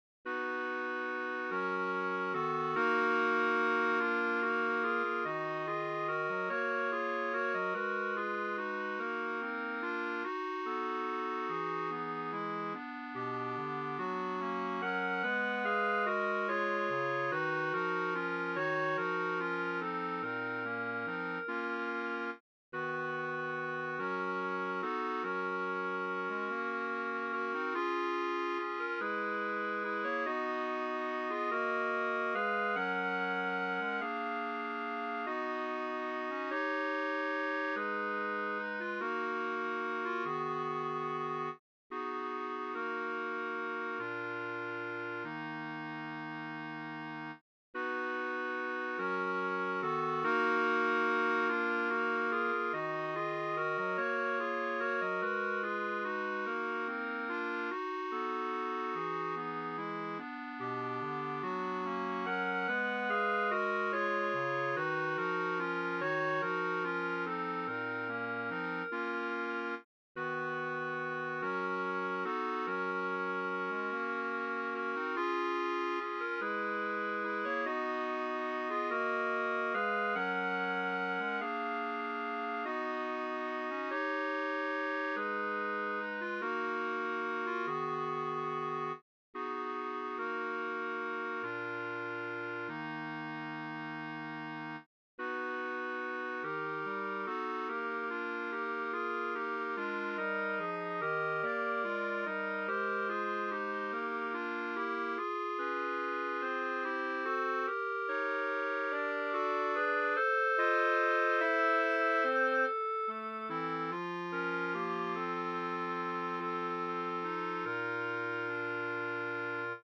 FOR 3 Bb CLARINETS, BASS CLARINET